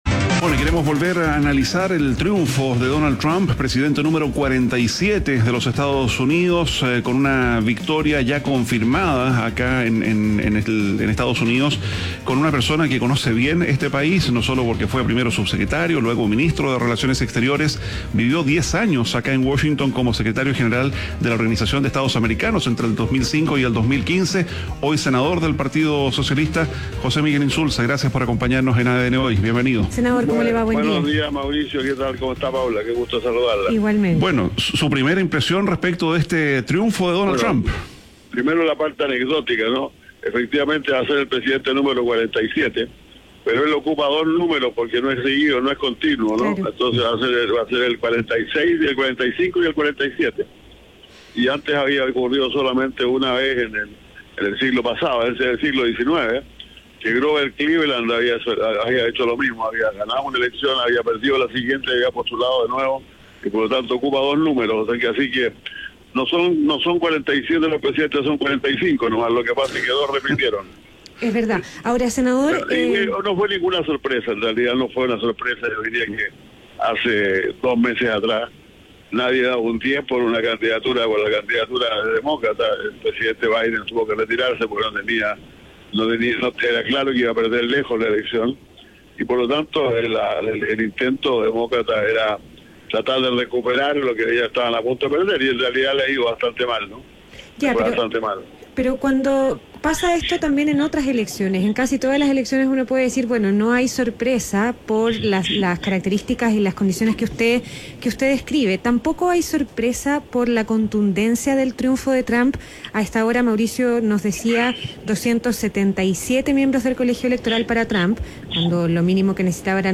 ADN Hoy - Entrevista a José Miguel Insulza, senador y exsecretario general de la OEA